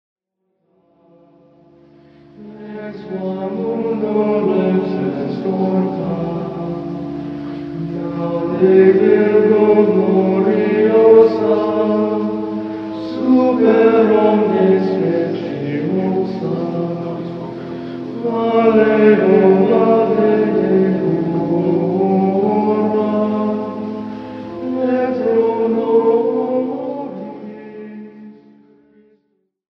A huge bargain on Gregorian Chant!
Interspersed with these are three Gregorian hymns as motets.